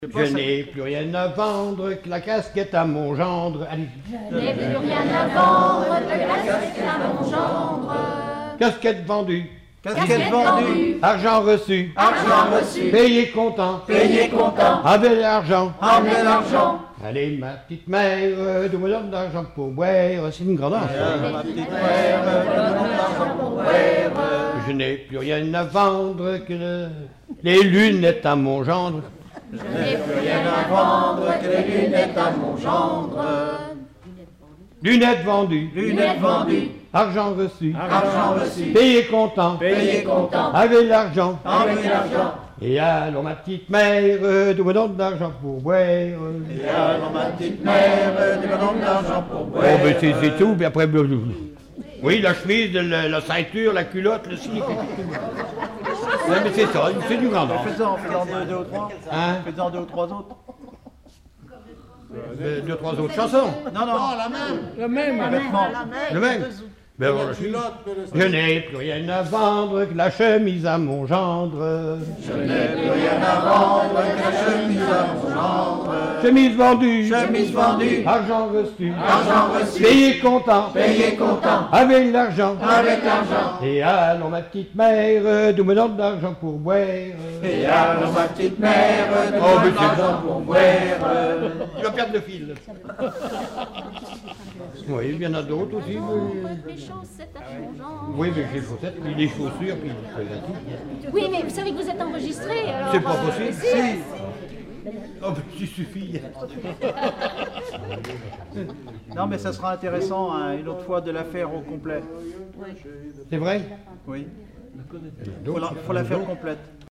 danse : ronde : grand'danse
Genre énumérative
Collectif-veillée (1ère prise de son)
Pièce musicale inédite